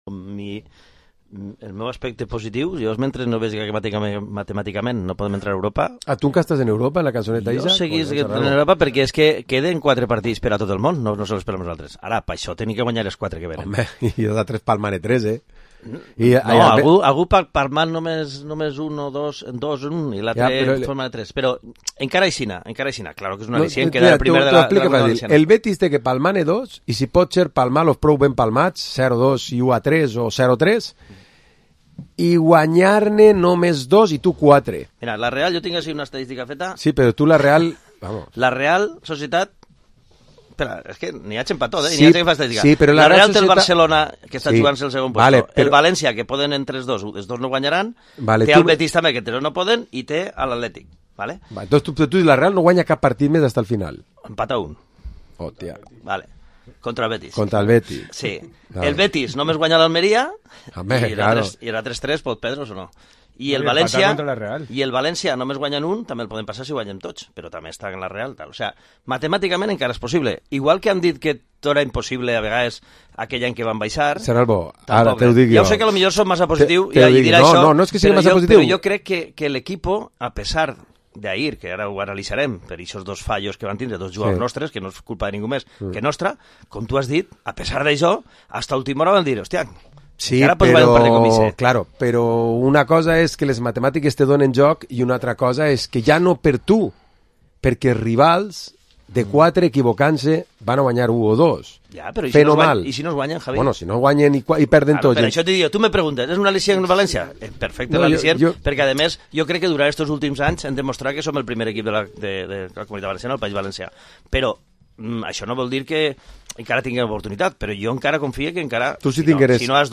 Programa esports tertúlia dilluns 6 de maig